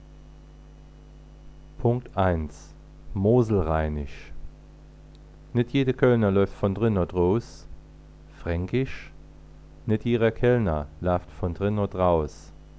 Elementare Tonbeispiele zur saarländischen Sprache
1. In der Region Nonnweiler/Weiskirchen (Nordsaarland) trifft noch heute einen eher niederrheinischen Dialekt an, der aus der Gegend von Köln stammen könnte.